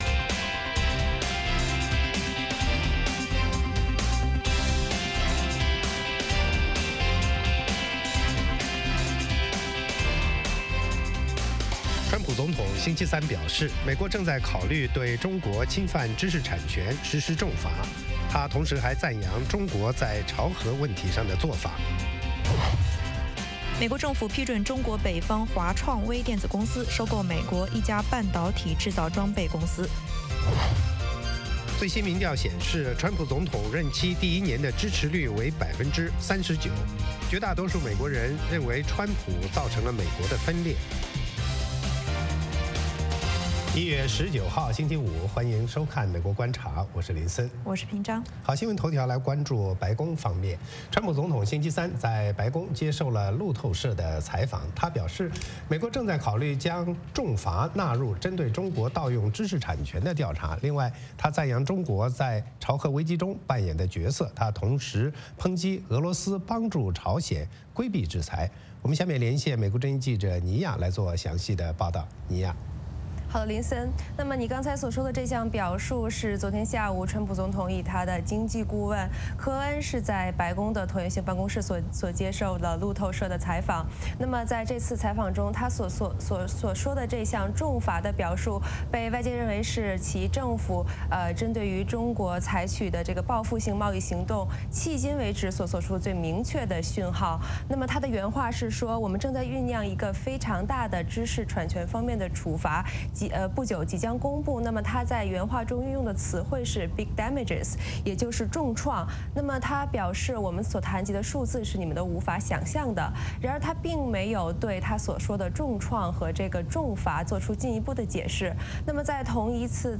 “VOA卫视 美国观察”掌握美国最重要的消息，深入解读美国选举，政治，经济，外交，人文，美中关系等全方位话题。节目邀请重量级嘉宾参与讨论。